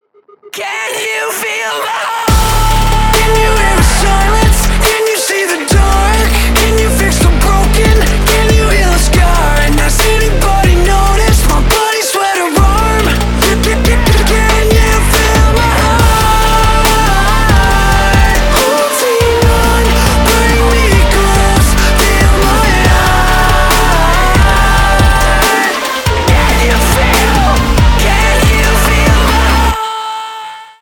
Ремикс
громкие